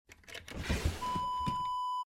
Dresser drawer open sound effect .wav #8
Description: The sound of a wooden dresser drawer being opened
Properties: 48.000 kHz 16-bit Stereo
A beep sound is embedded in the audio preview file but it is not present in the high resolution downloadable wav file.
Keywords: wooden, dresser, drawer, pull, pulling, open, opening
drawer-dresser-open-preview-8.mp3